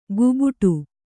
♪ gubuṭu